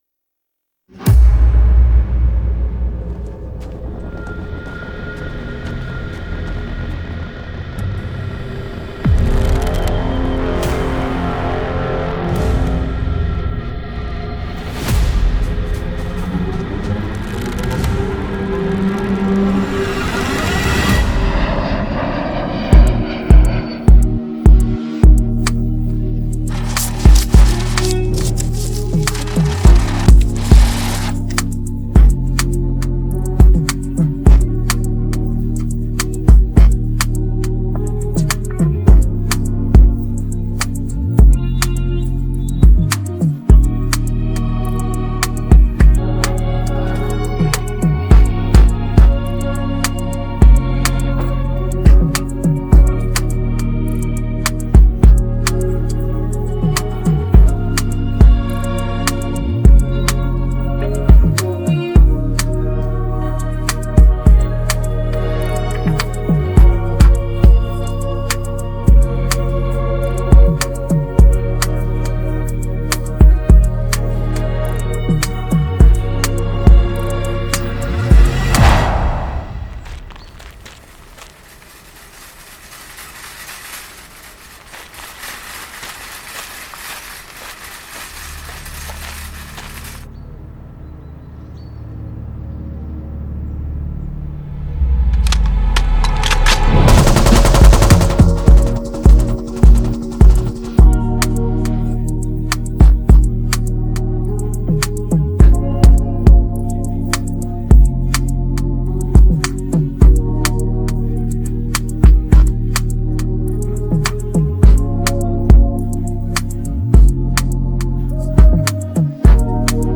Hip hophiphop trap beats